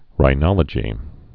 (rī-nŏlə-jē)